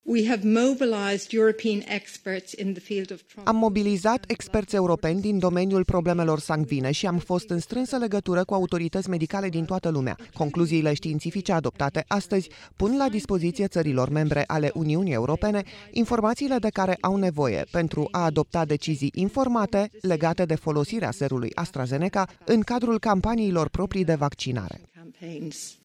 Într-o conferință de presă, directoarea forului, Emer Cooke, a spus că serul nu poate fi asociat cu un risc major de tromboză, dar va cere firmei producătoare să includă acest risc în cadrul prospectului.